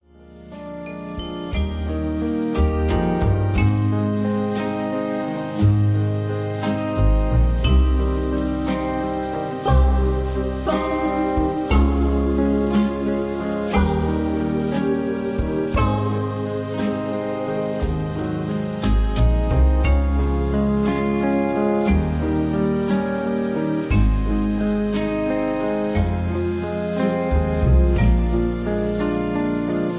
backing tracks
rock and roll